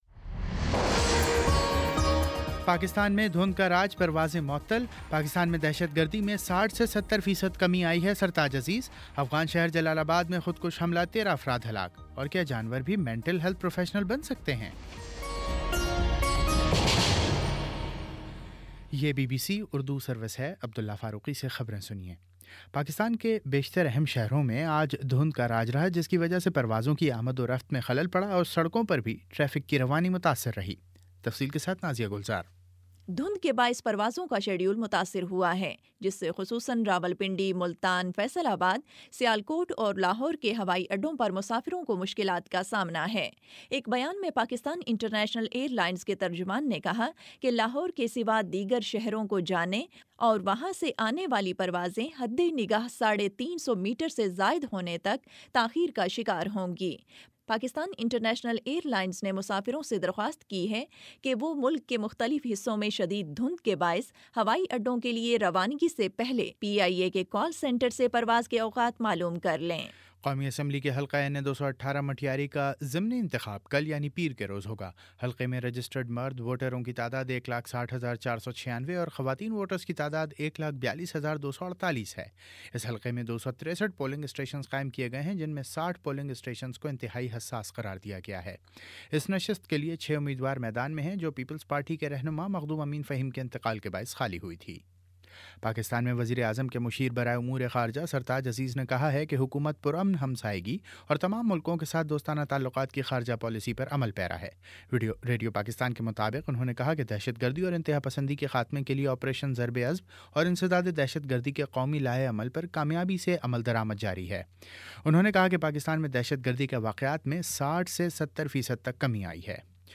جنوری 17 : شام سات بجے کا نیوز بُلیٹن